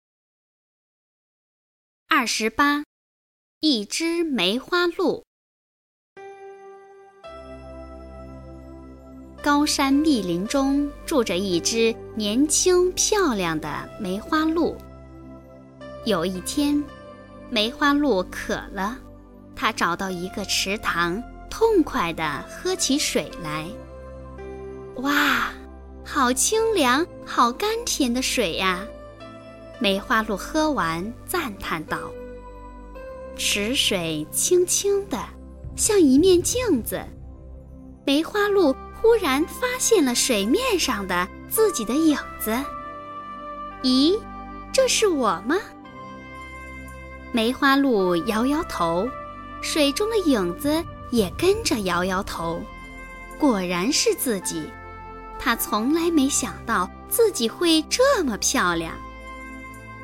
语文三年级上西师版28《一只梅花鹿》课文朗读_21世纪教育网-二一教育